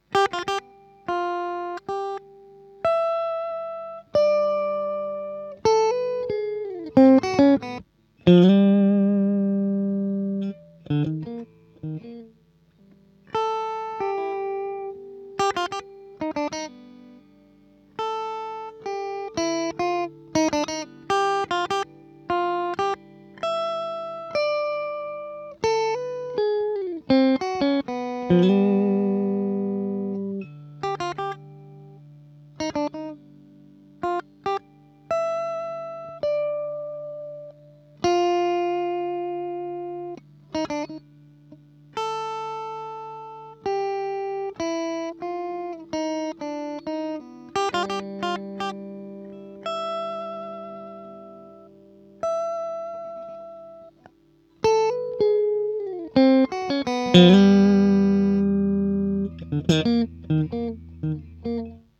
CHITARRA ELETTRICA
Entrambe le chitarre sono affiancate da una testata Hughes & Kettner un'amplificazione valvolare capace di esaltare le specificità timbriche di ogni strumento e particolarmente adatta per gli spazi medi in cui mi esibisco.
Estratto "Serenata per un satellite" (B.Maderna), suono neutro senza pedaliera